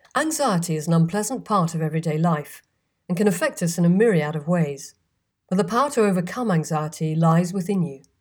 Female voice quality - equalisation advice appreciated
The sample recording I’ve received is too low and in stereo, both of which I can easily change, but the actual vocal quality has something of a boom to it.
I’d bet ACX are not going to accept that because of the loud reverberation in the room.
That almost sounds like recording in the bath.
But that honking is a little unusual.
That voice distortion could be extreme echo cancellation and automatic noise reduction.